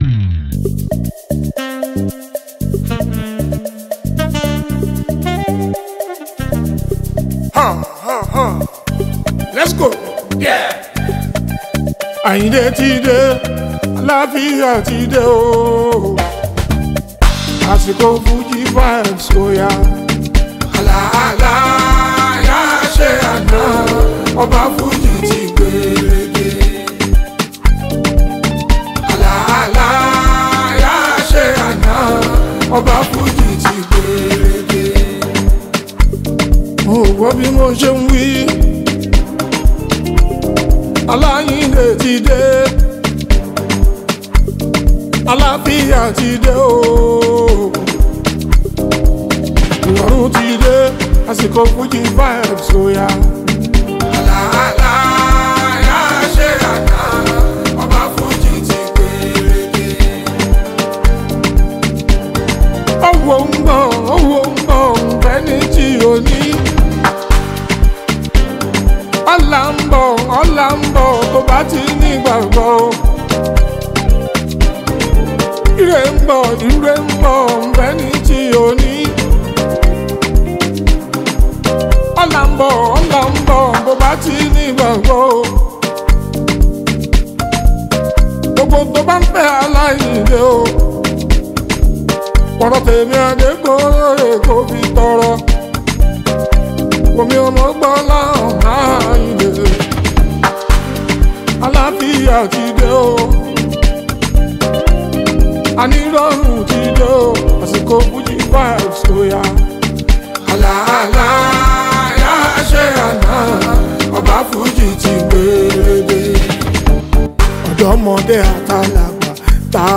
Fuji, Highlife
Nigerian Yoruba Fuji track
especially if you’re a lover of Yoruba Fuji Sounds